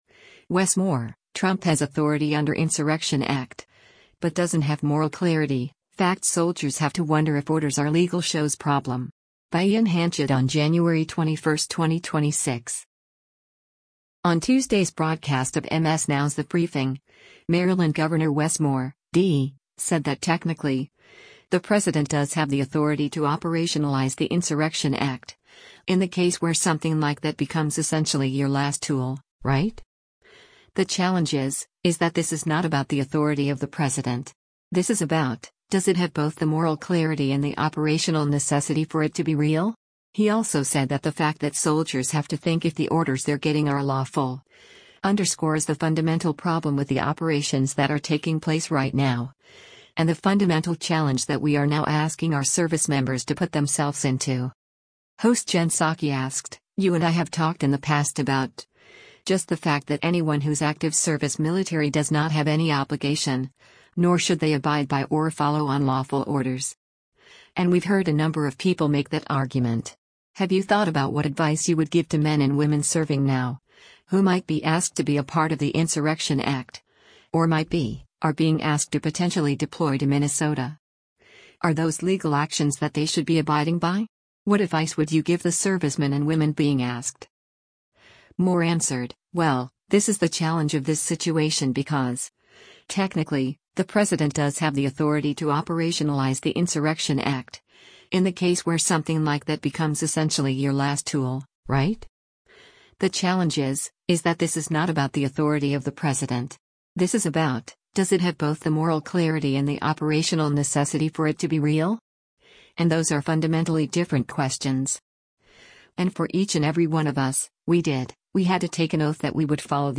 On Tuesday’s broadcast of MS NOW’s “The Briefing,” Maryland Gov. Wes Moore (D) said that “technically, the president does have the authority to operationalize the Insurrection Act, in the case where something like that becomes essentially your last tool, right?